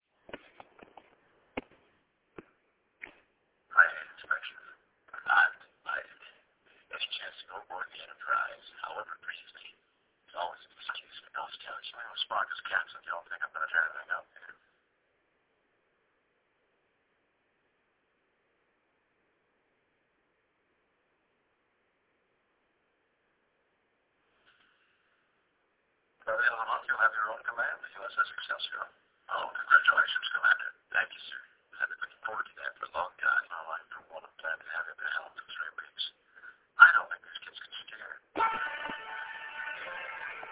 Quality is low, but the rarity of these materials makes them an absolute treasure.
And I have to say Takei's super passionate reading didn't help.